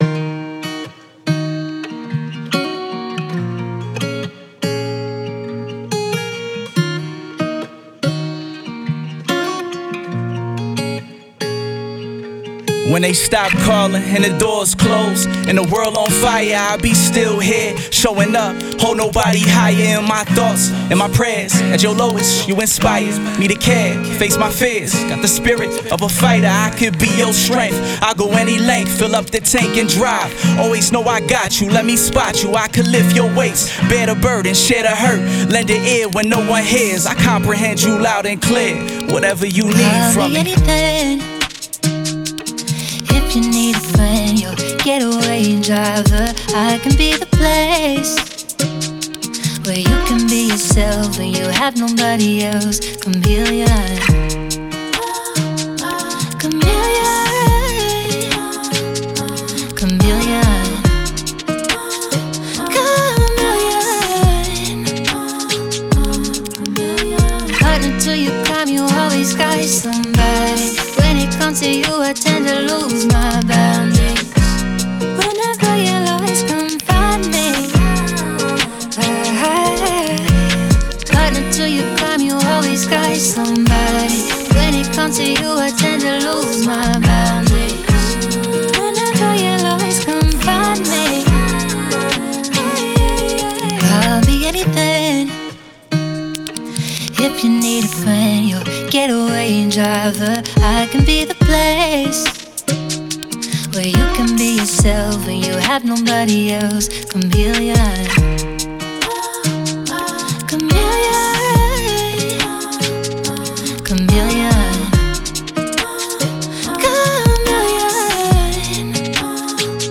Hip Hop, R&B, Soul
Ebmin